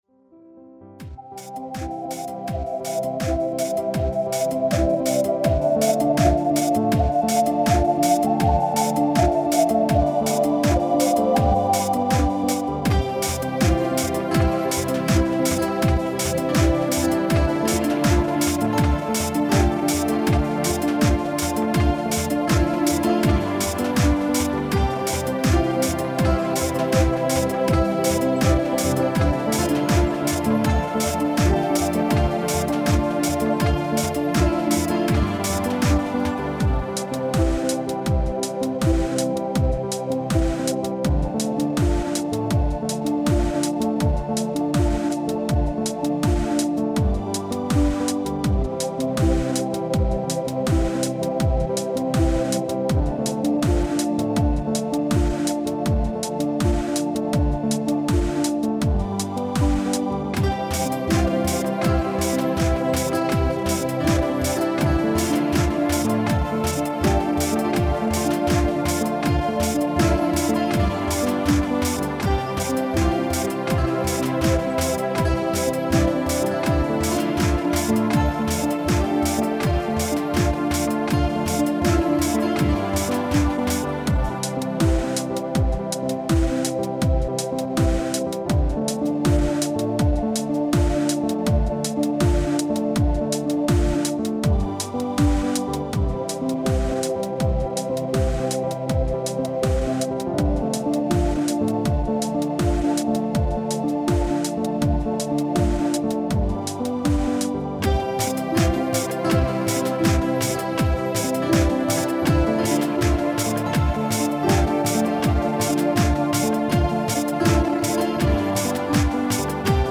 Фонограмма: